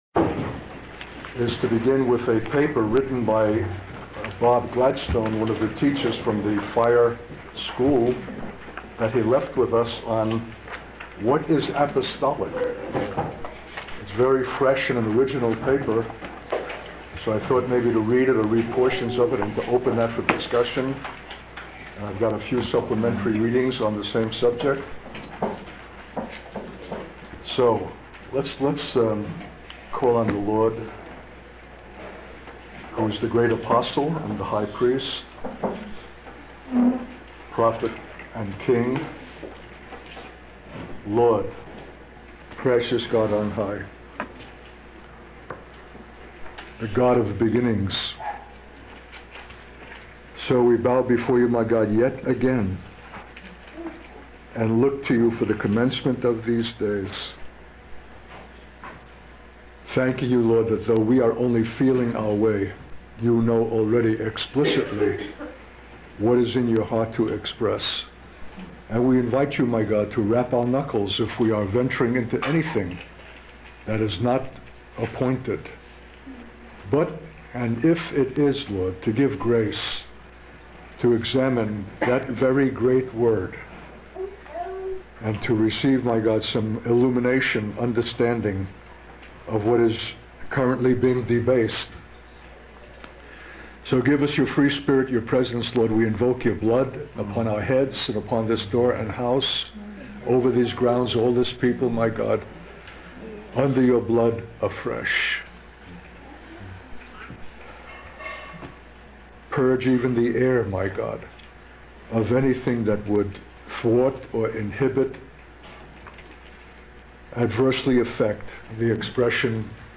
In this sermon, the speaker discusses the concept of apostolic ministry and its distinction from other forms of ministry. He begins by describing a powerful and transformative experience that a person goes through when they realize that their entire life has been a lie and that they have been persecuting God.